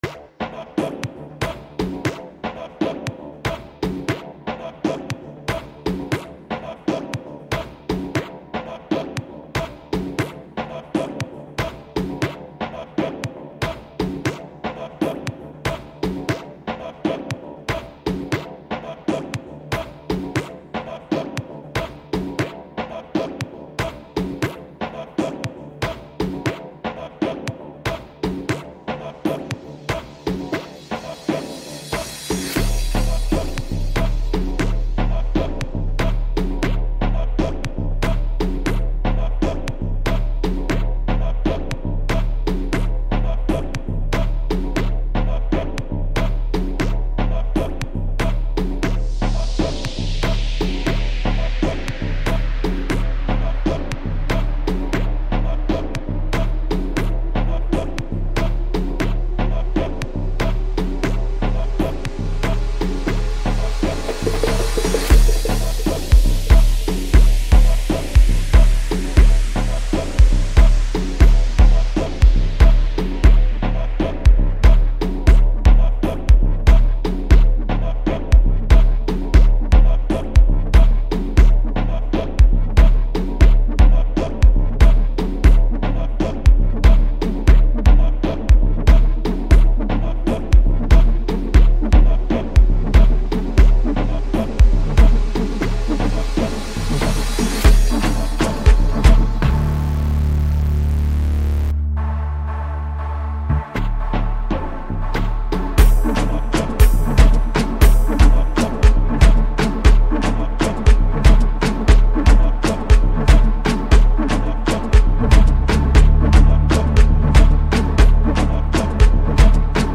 Home » Amapiano » DJ Mix » Hip Hop
South African singer-songsmith